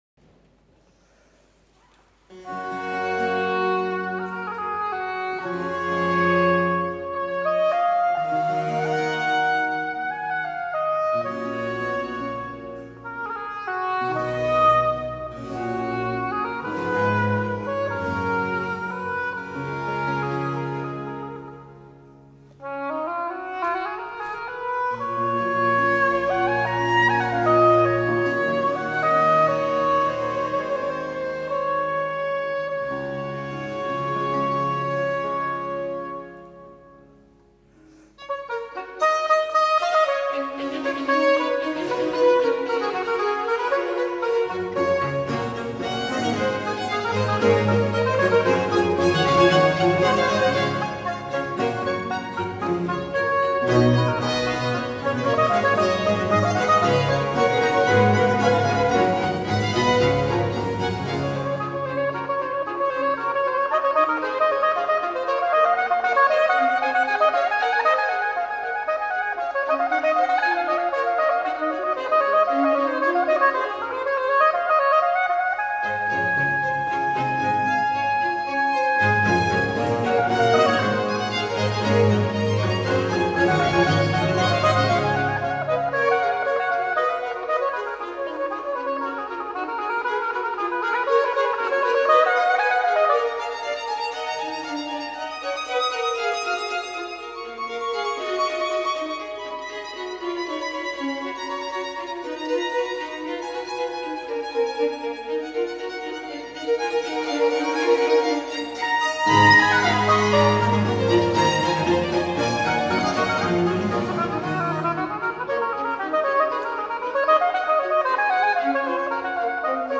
Georg Friedrich Händel: Concerto grosso op. 3, Nr. III, G-Dur, HWV 314 (Live-Mitschnitt des Konzerts vom 16.07.2017 in Benediktbeuern)
Largo e staccato – Allegro
Allegro